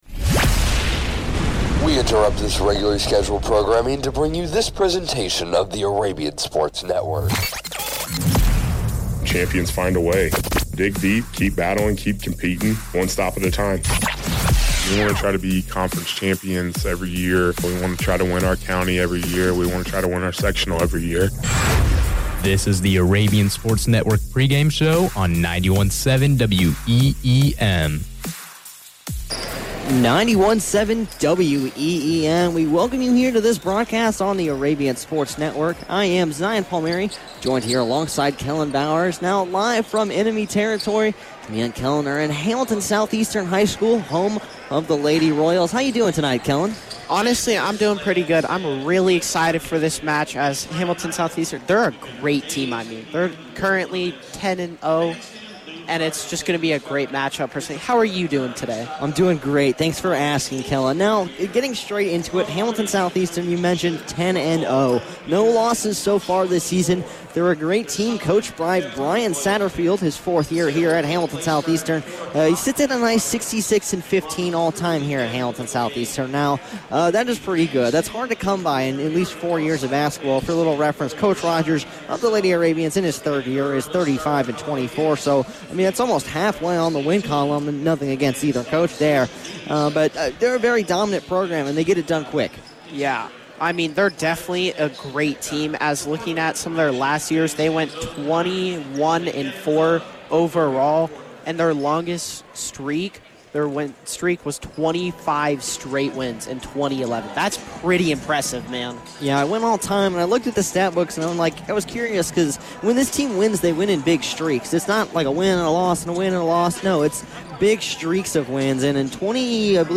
Varsity Girls Basketball Broadcast Replay Pendleton Heights vs. Hamiliton Southeastern 12-10-24